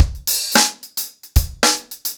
DaveAndMe-110BPM.31.wav